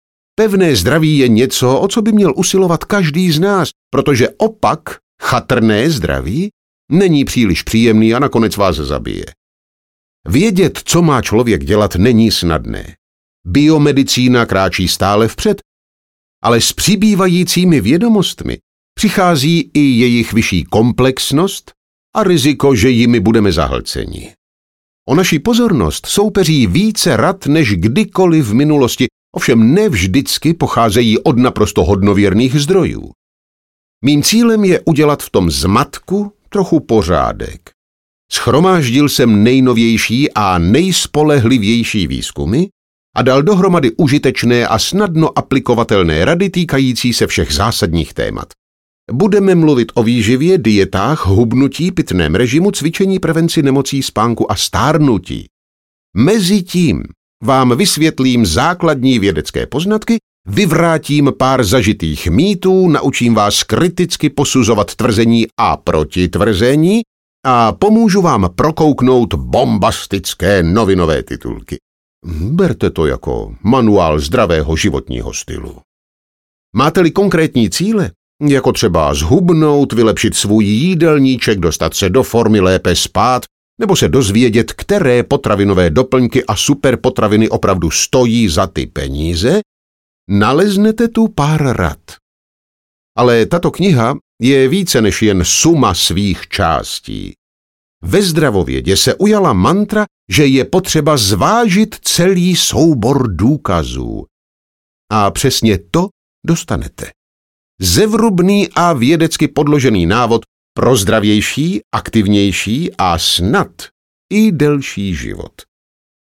Tahle kniha vám možná zachrání život audiokniha
Ukázka z knihy